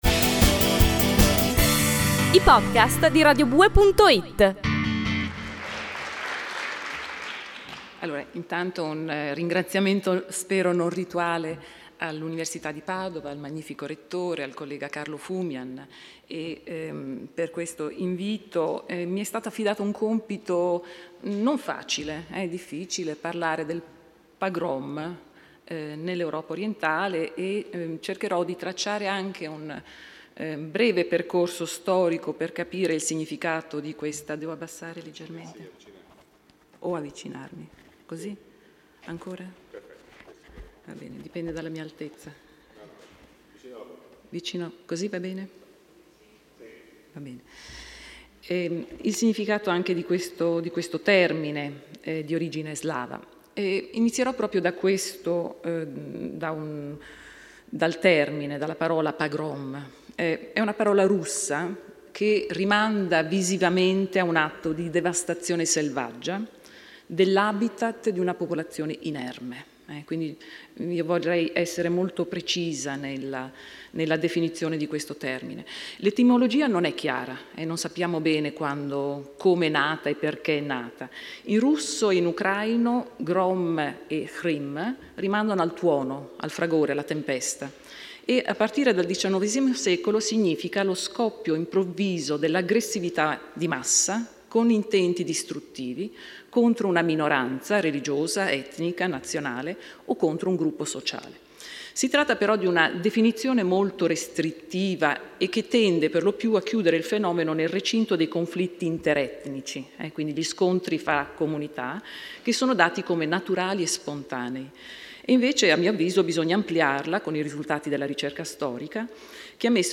lectio magistralis
L’evento è stato organizzato in occasione della Giornata della Memoria (ASCOLTA COSA HA DETTO IL RETTORE)